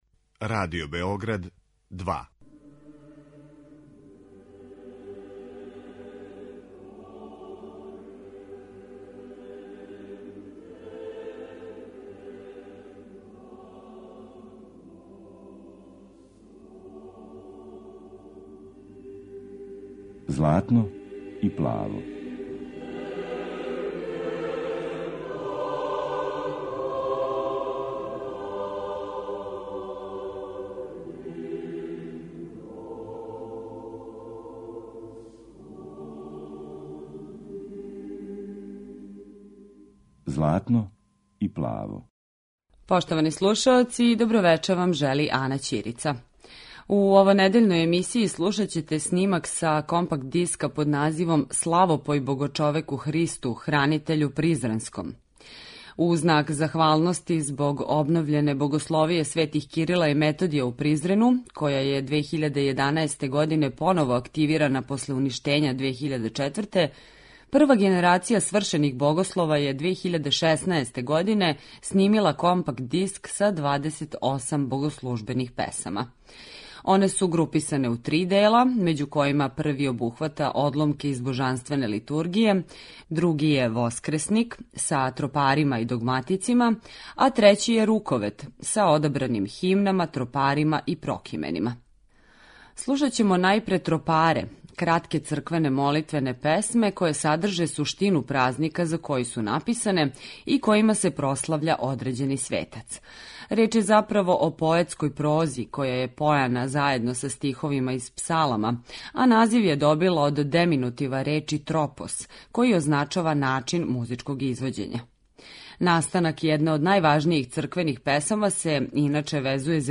Појање призренских богослова
Снимци су остварени у храму Богородице Љевишке у Призрену.
Емисија посвећена православној духовној музици.